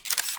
camera_flash.wav